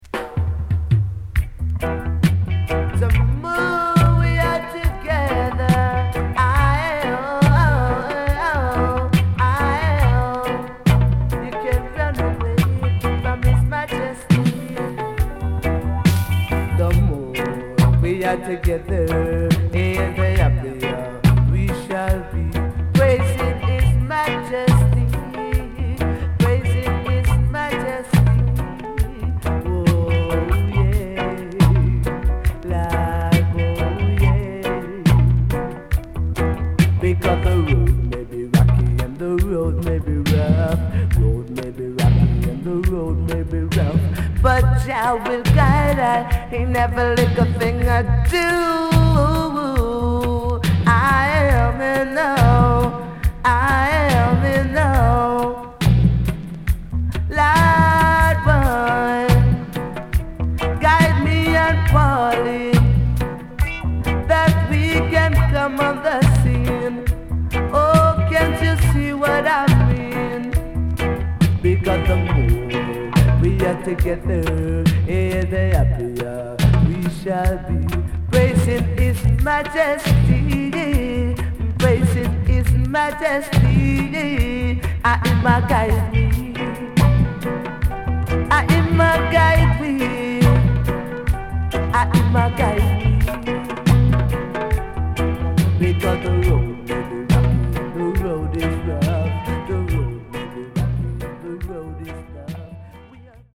HOME > Back Order [DANCEHALL LP]
マイナー調のHeavyな曲が多いです。
SIDE A:少しチリノイズ入りますが良好です。